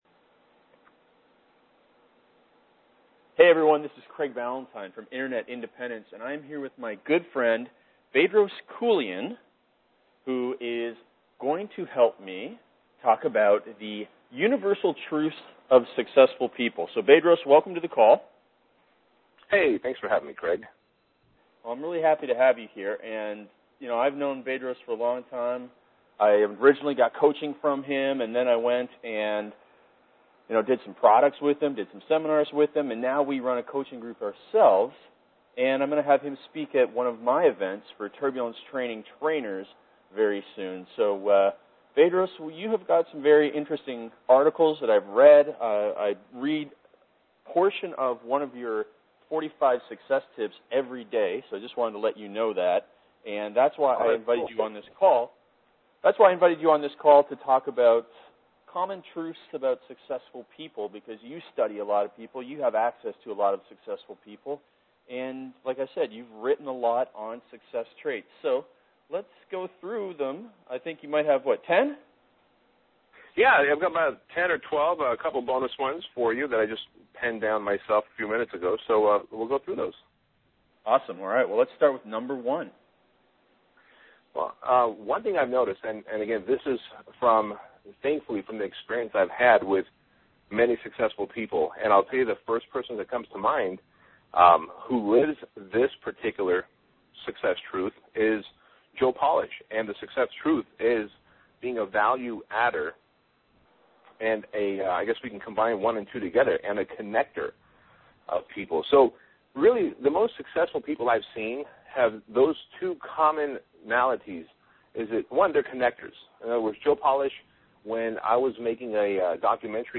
The interview was about the universal traits that the most successful people in business have.